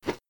Swing Woosh